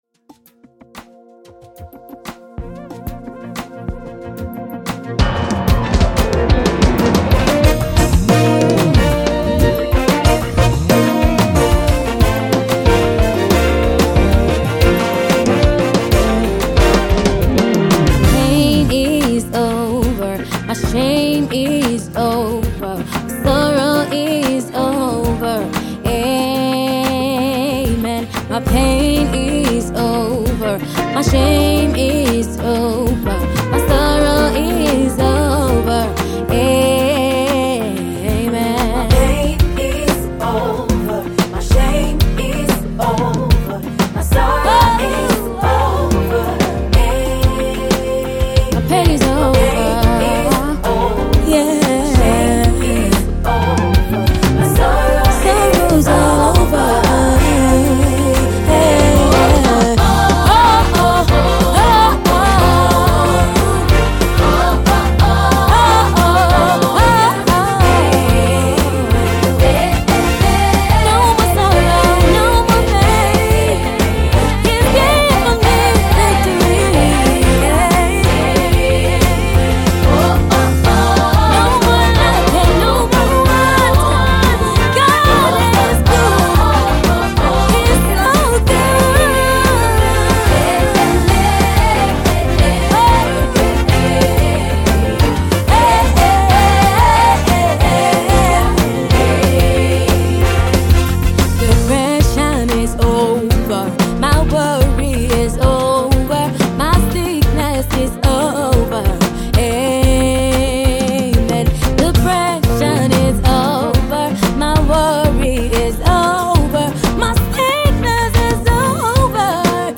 a song of faith and positive declaration.